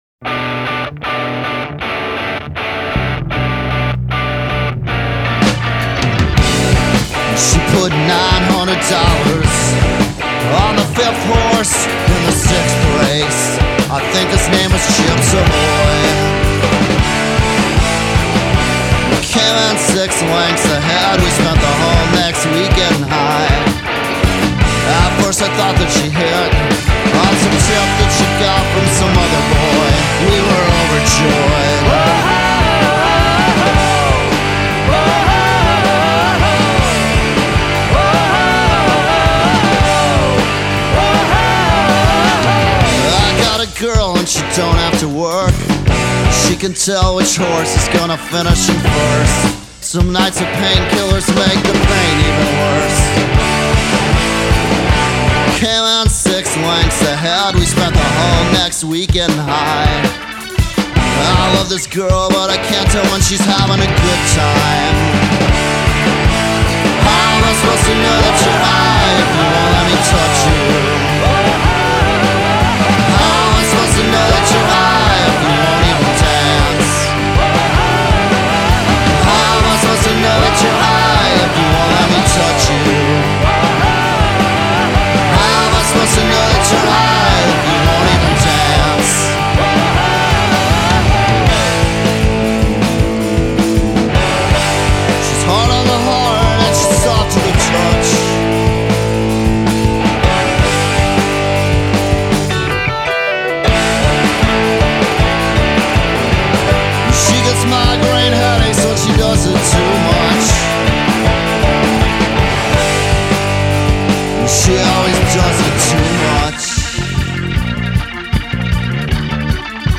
banda de indie rock americana do Brooklyn